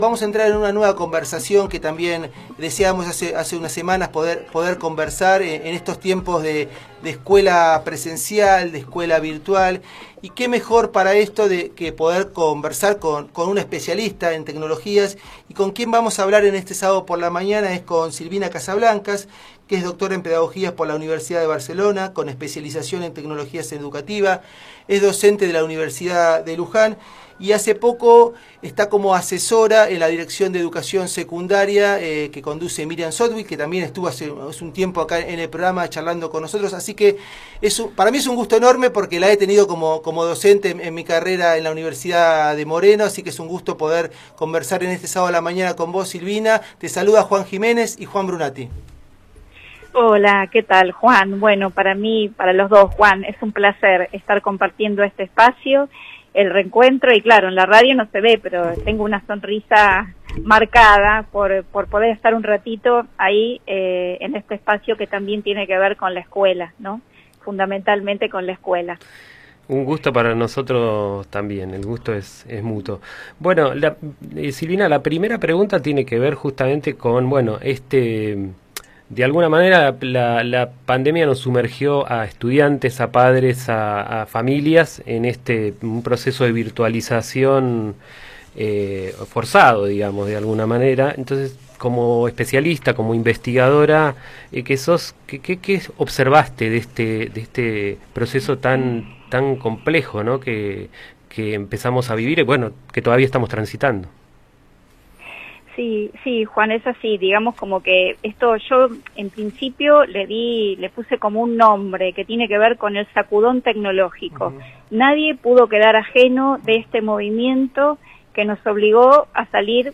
Entrevista para FM Rec 895 sobre la complejidad educativa en la pandemia.